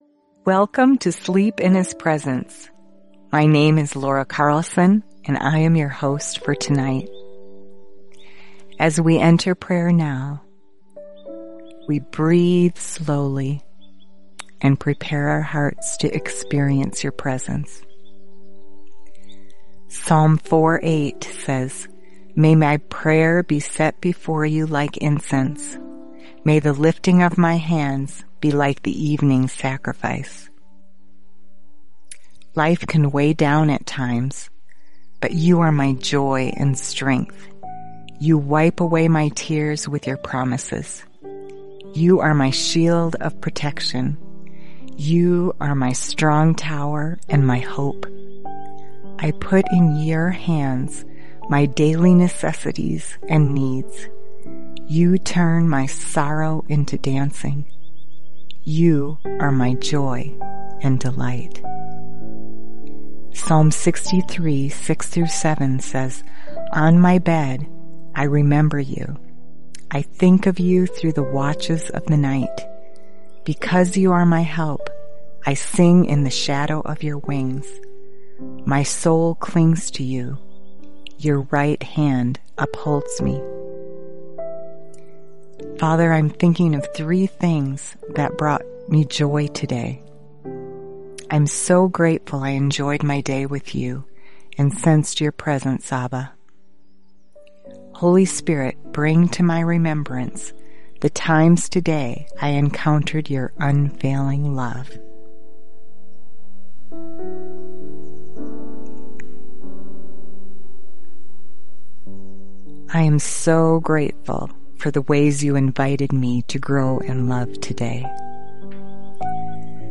In this 6-day devotional, you’ll be gently led into the presence of God through heartfelt prayers, encouraging words, and powerful Scripture. Each night, a different host guides you to release your worries, embrace God’s love, and fall asleep knowing He is near.